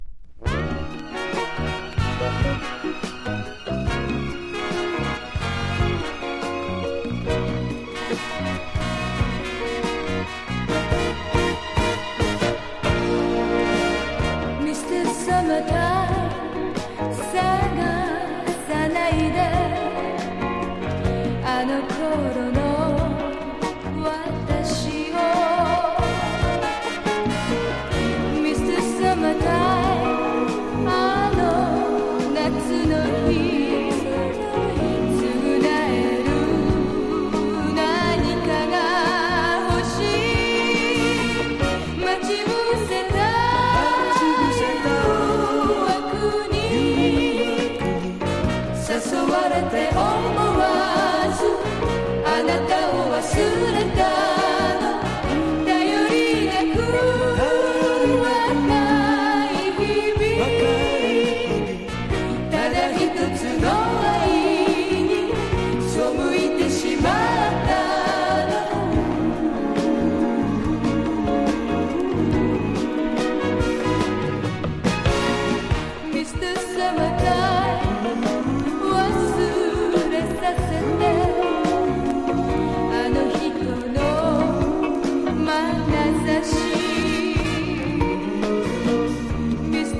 CITY POP / AOR